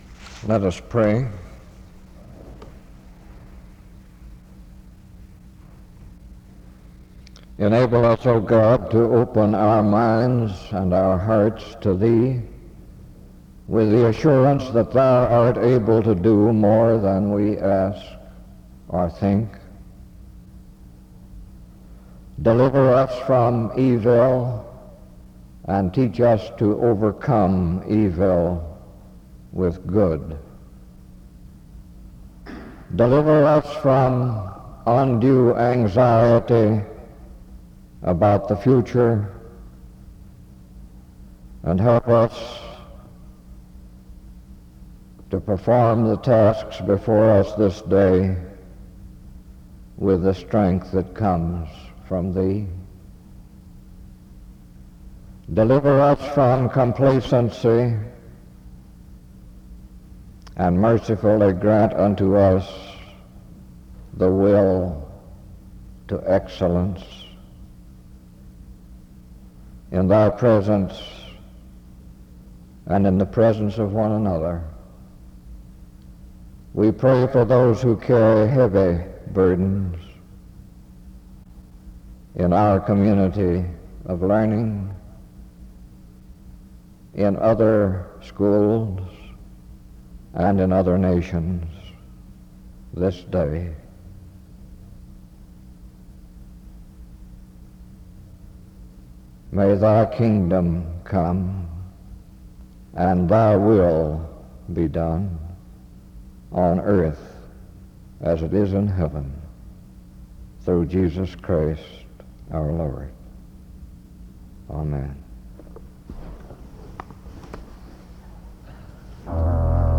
He leads a responsive reading from 2:12-4:18.
He preaches from 6:52-26:22. He preaches on the courage to seek God.